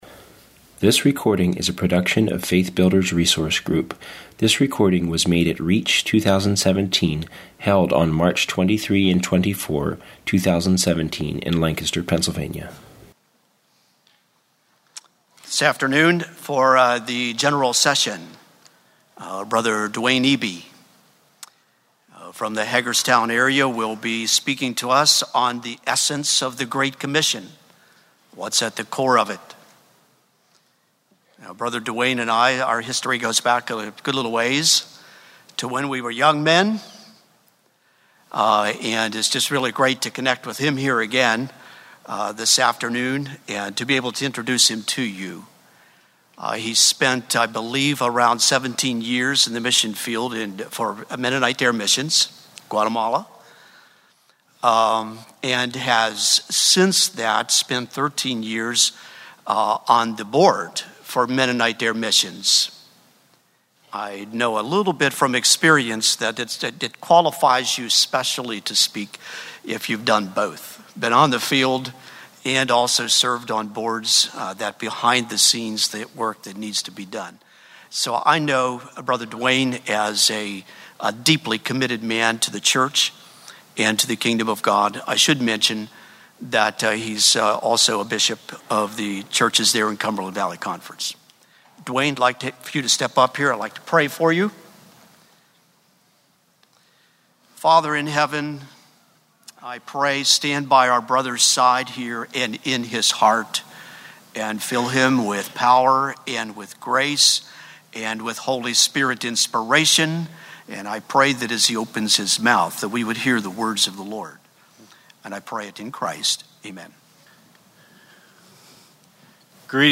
Home » Lectures » The Essence of the Great Commission